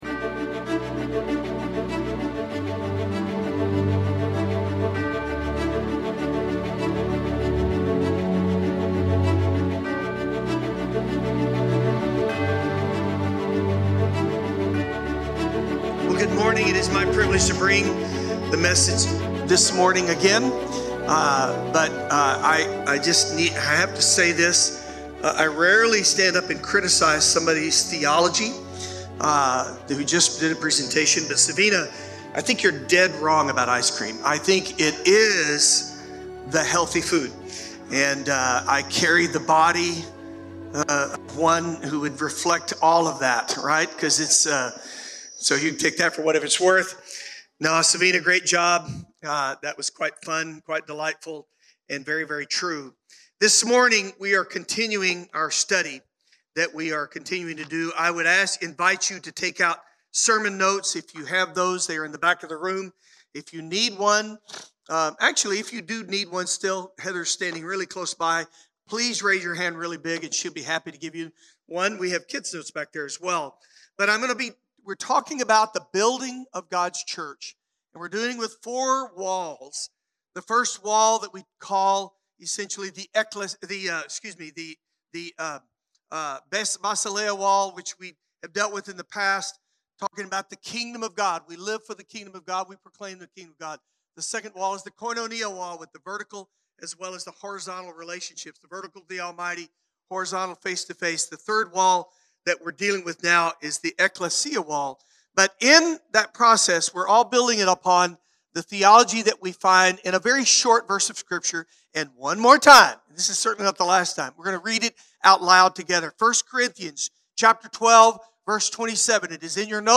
Download the sermon notes (adult version)